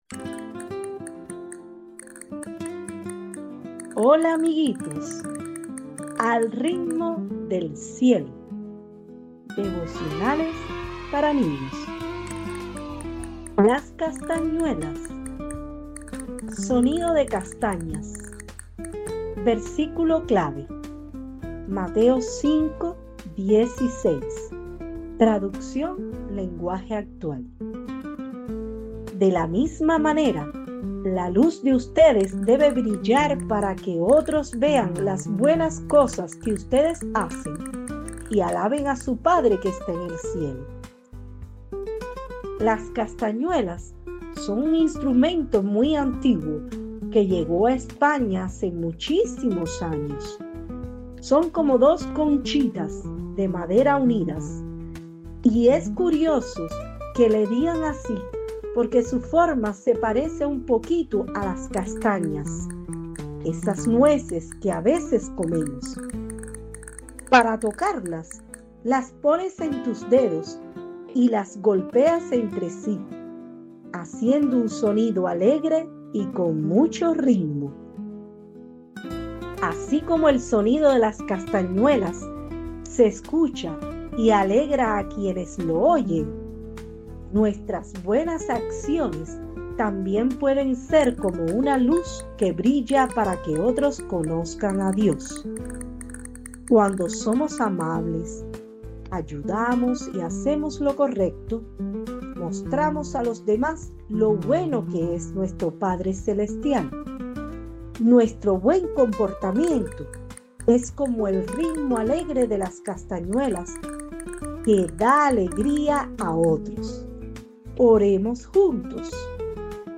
Sonido de Castañas